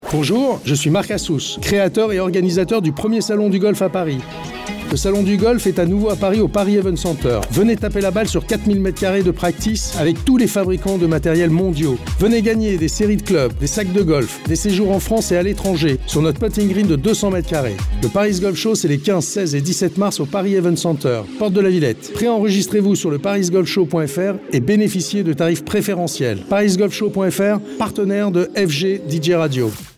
PUBLICITÉ SPOT RADIO
spot-radio.mp3